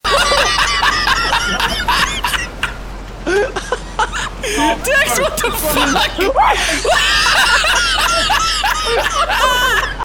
Play, download and share Hahaha WTF original sound button!!!!
hahaha-wtf.mp3